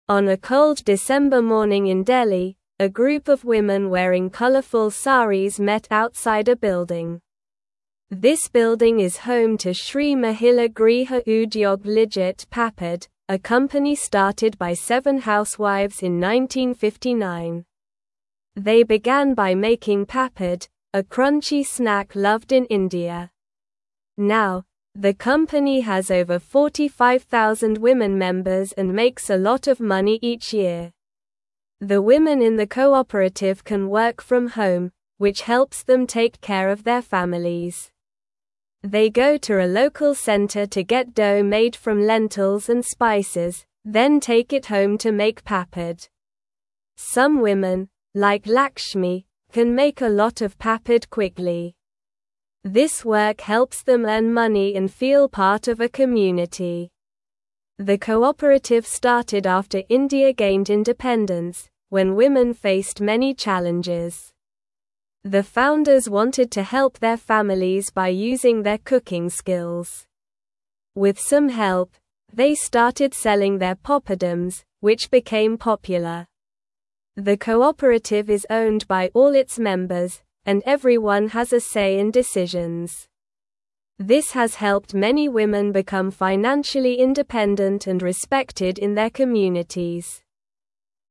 Slow
English-Newsroom-Lower-Intermediate-SLOW-Reading-Women-in-India-Make-Tasty-Papad-Together.mp3